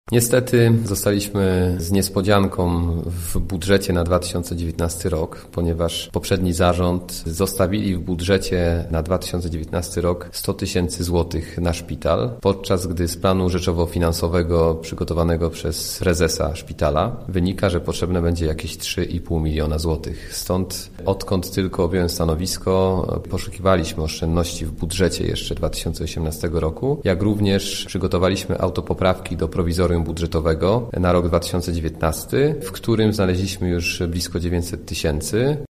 – Jak się okazuje w budżecie powiatu zapisano bardzo niewielkie środki na funkcjonowanie szpitala – mówi Grzegorz Garczyński, starosta krośnieński.